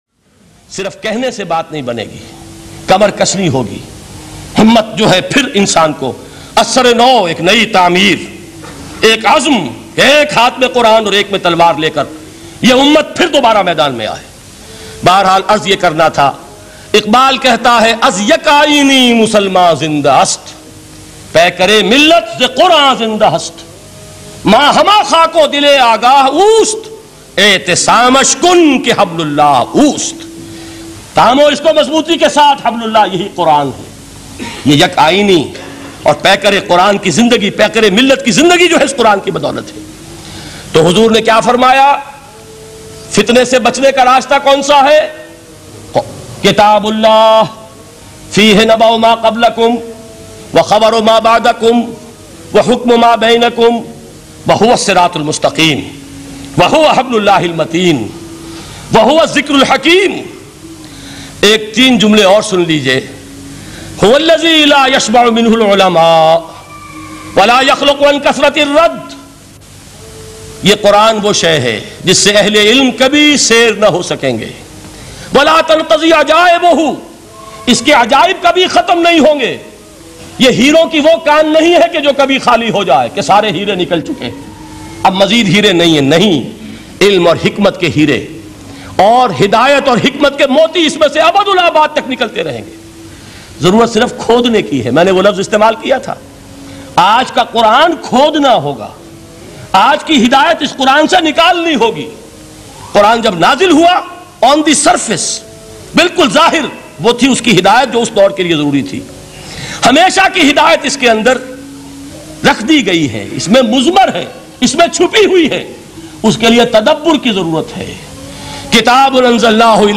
Quran Ki Azmat Bayan MP3 Download By Dr Israr Ahmad